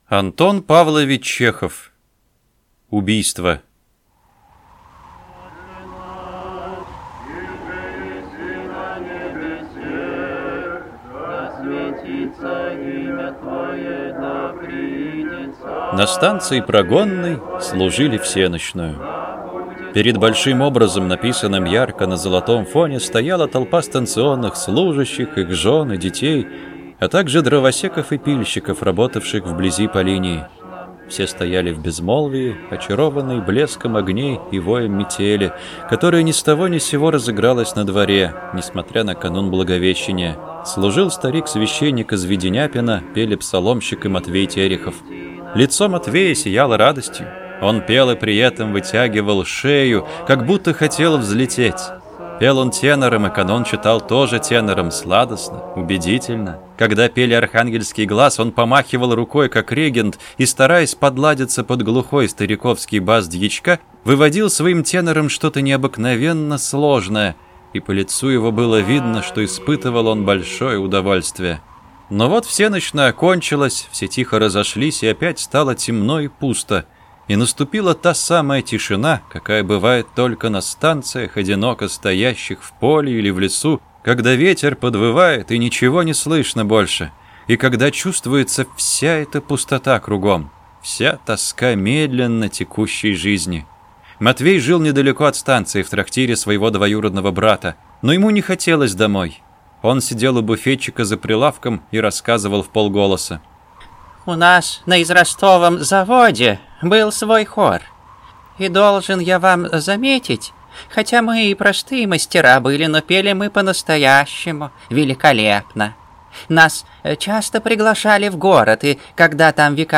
Аудиокнига Убийство | Библиотека аудиокниг